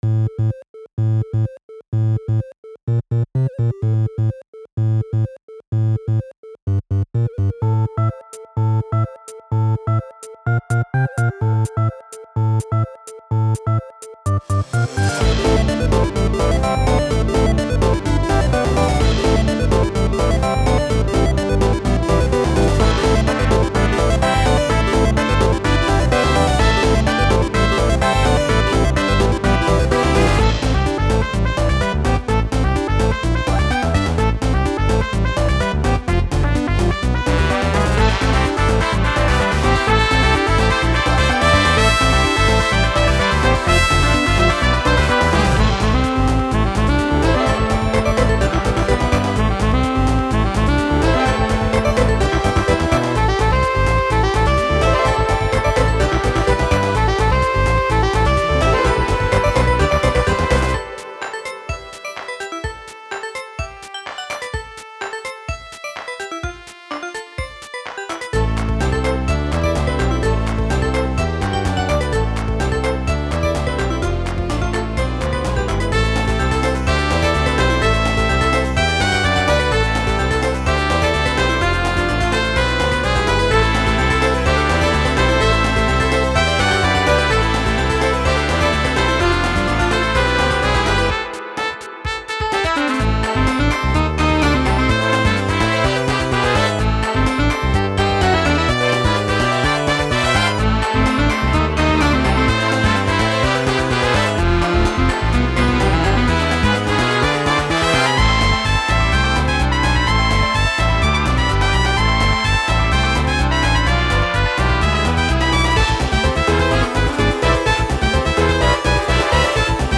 Another Boss theme from a cancelled project.